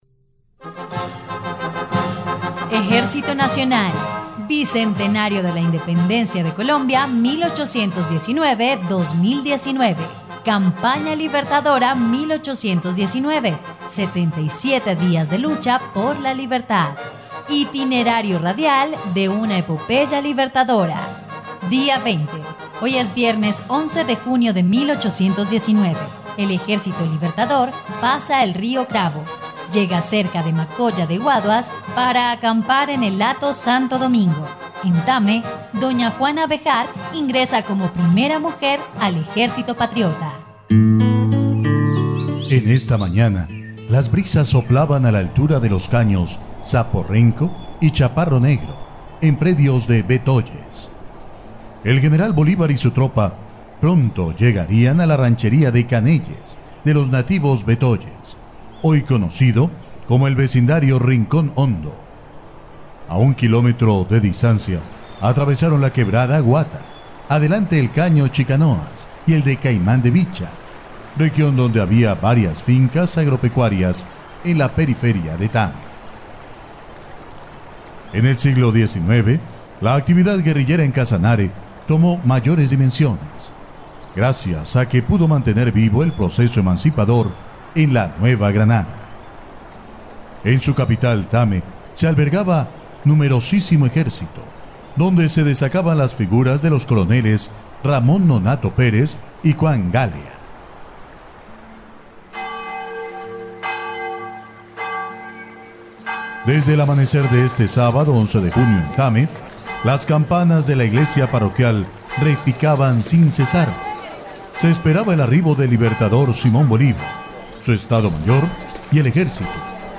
dia_20_radionovela_campana_libertadora.mp3